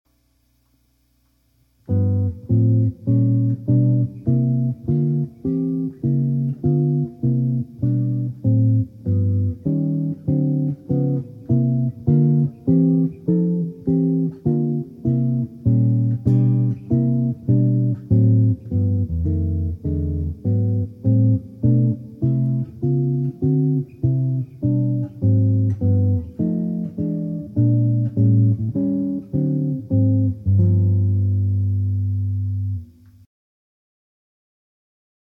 Gattung: Gitarre
Besetzung: Instrumentalnoten für Gitarre